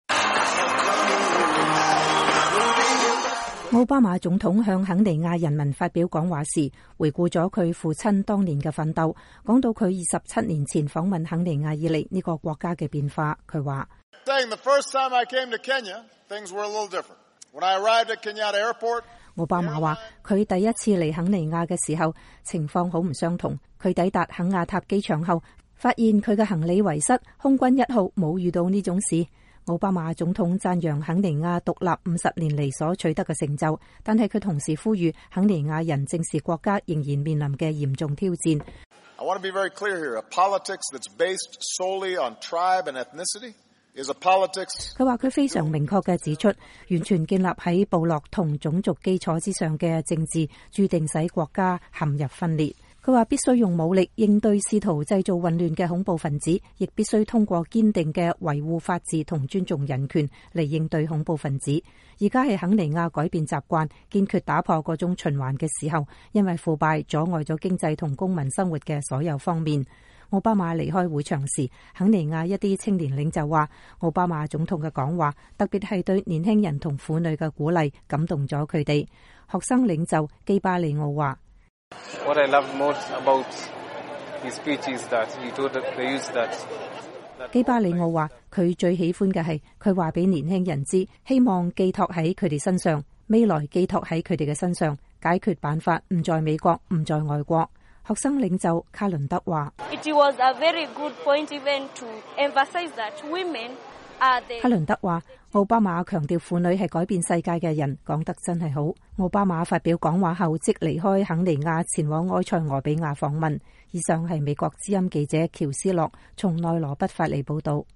美國總統奧巴馬星期天結束對肯尼亞的訪問之前，在內羅畢郊外一個體育場發表重要講話。